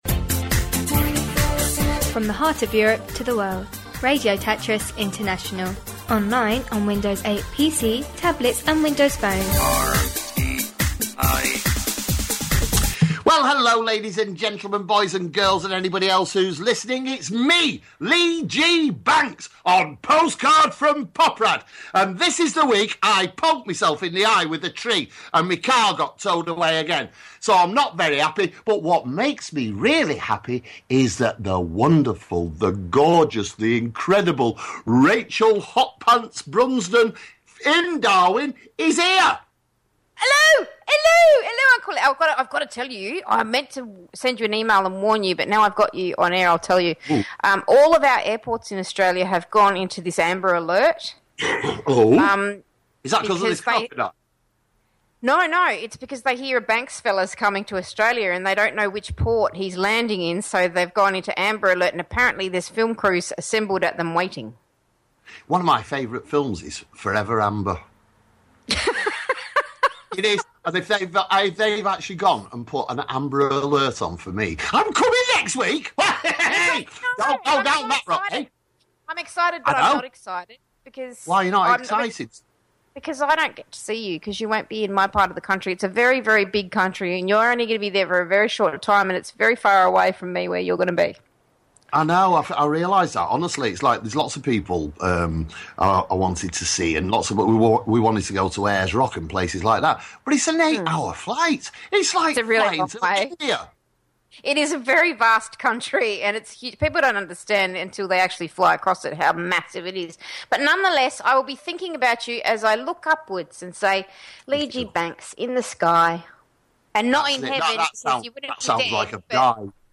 Everything from Tech to Tabloid News.